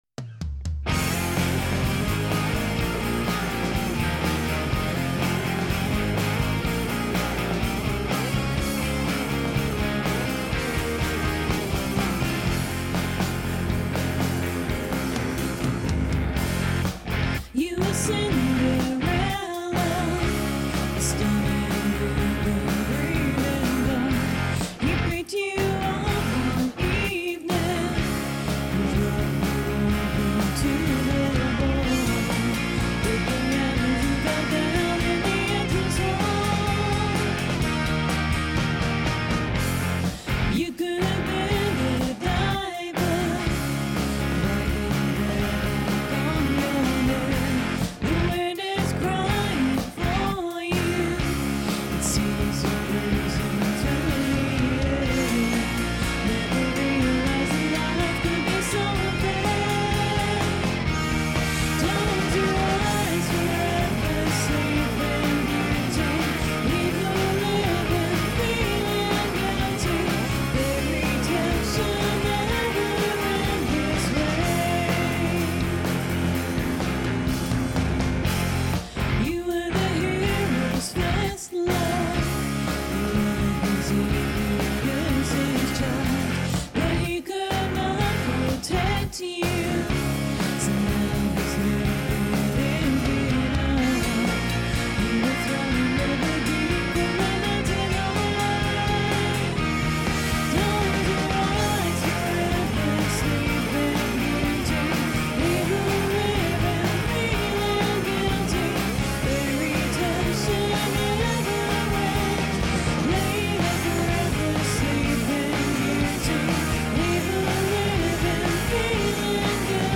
rock band
on vocals and rhythm guitar
drums
Bass
A: Girl-fronted-rock!